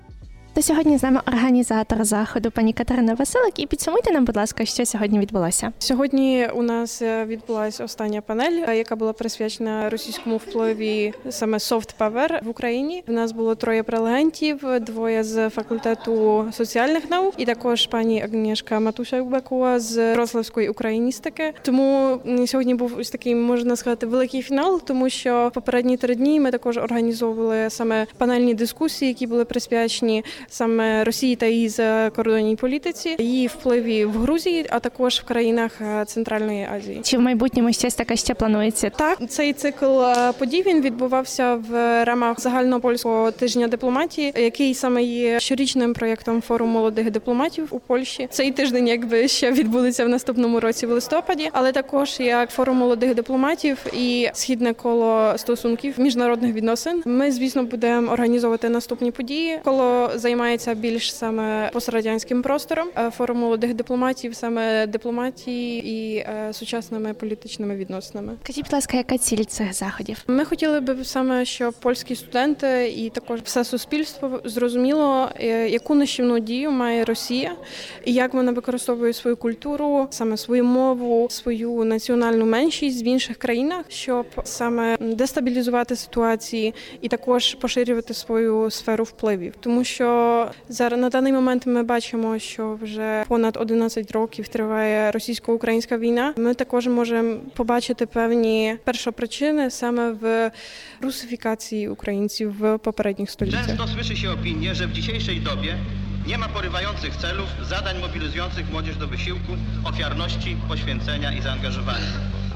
Spotkania odbywały się w budynku Instytutu Politologii Uniwersytetu Wrocławskiego.